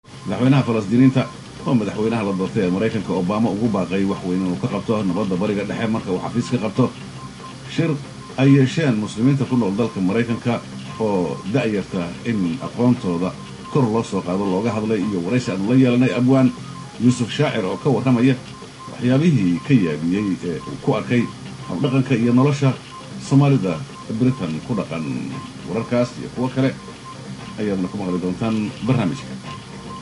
Here’s part of a news report in a mystery language.